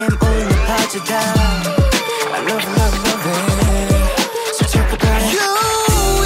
OS_HHH_SFX_115_Scratch_3